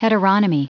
Prononciation du mot heteronomy en anglais (fichier audio)
Prononciation du mot : heteronomy